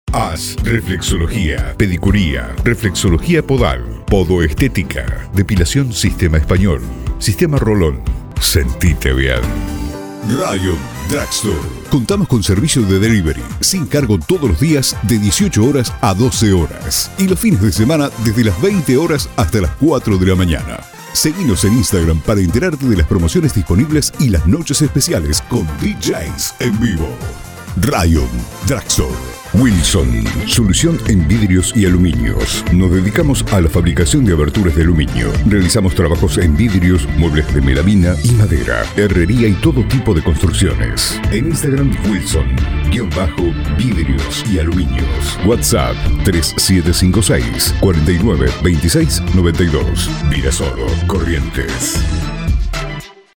voice over
Demo-comerciales.mp3